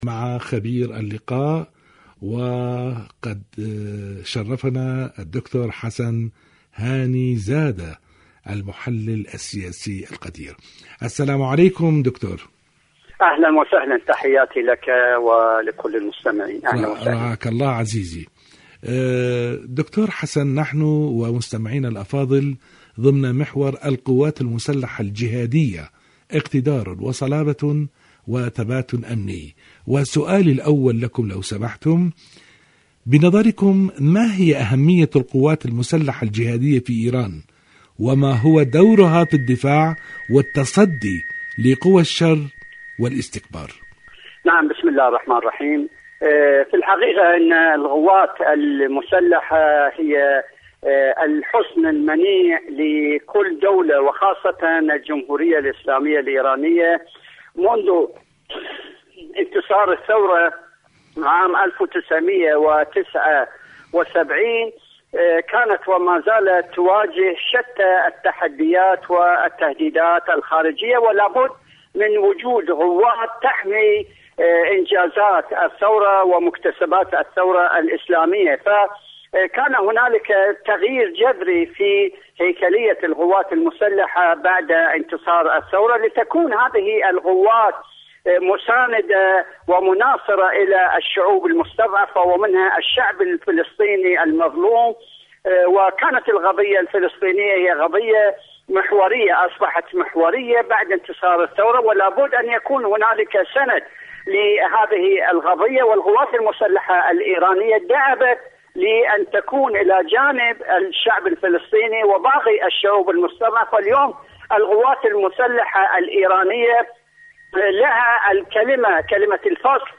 إذاعة طهران-فجر الانتصار الـ 42: مقابلة إذاعية